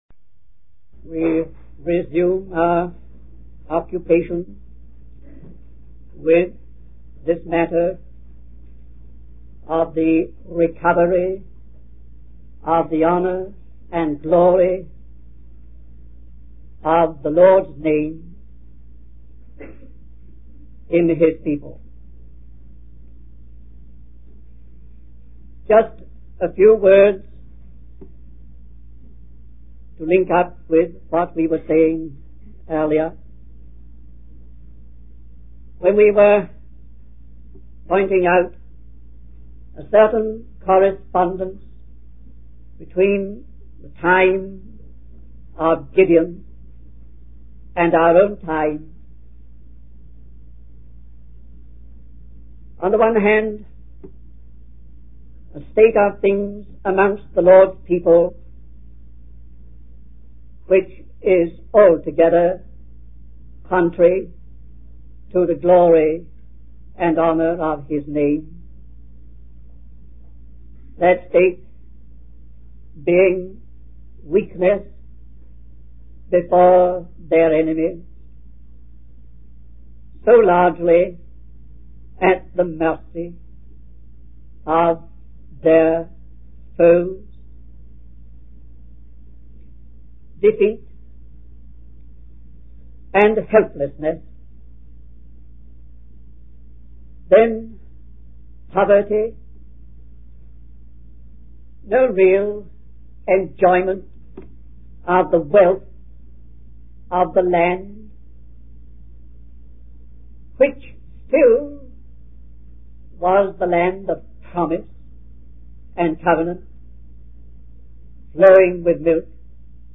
In this sermon, the preacher focuses on the story of Gideon from the Bible.